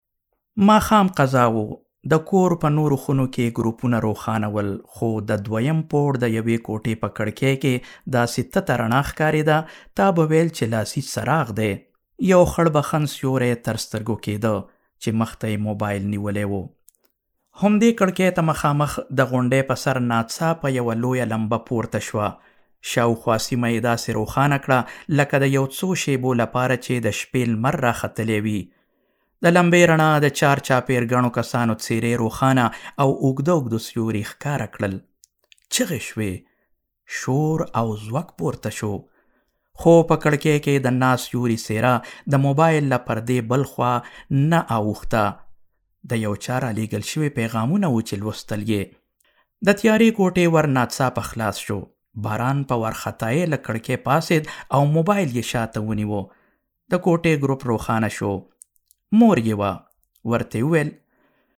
Male
Adult
Narration